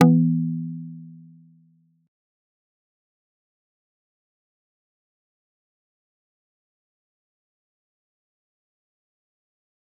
G_Kalimba-E3-pp.wav